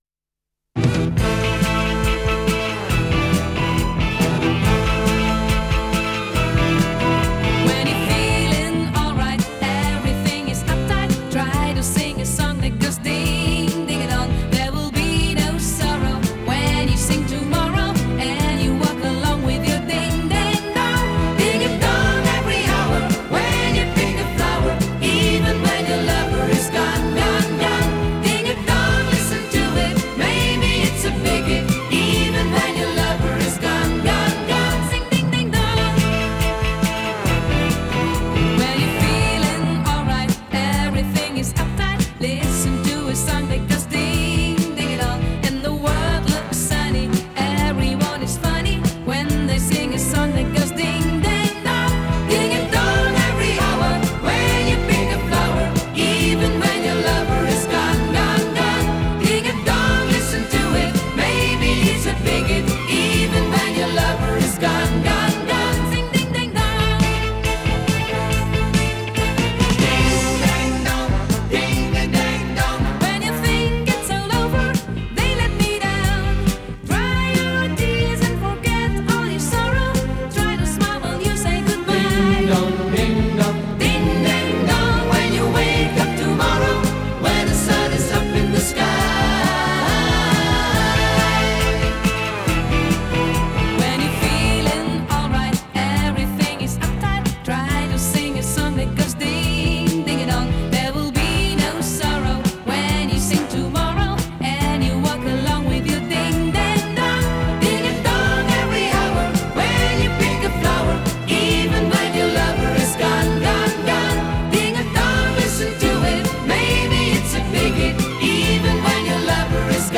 Жанр: Electronic, Rock, Funk / Soul, Pop
Recorded At – Soundpush Studios